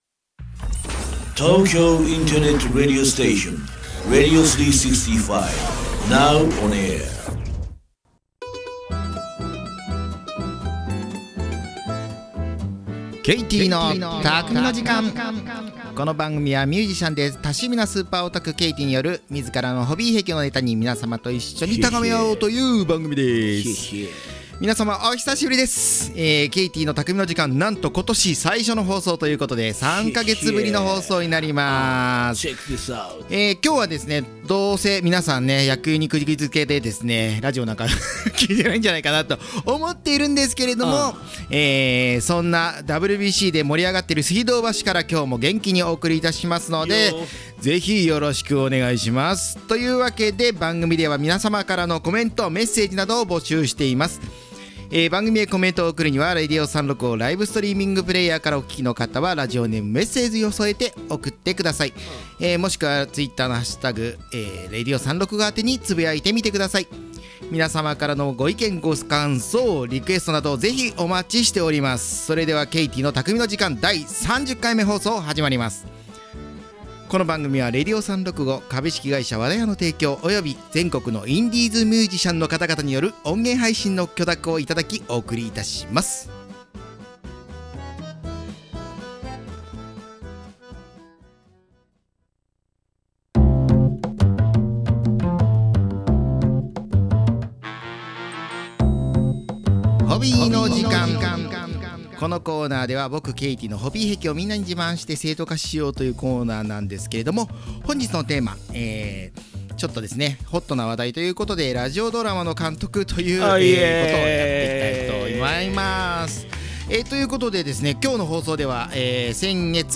【この音源は生放送のアーカイブ音源となります】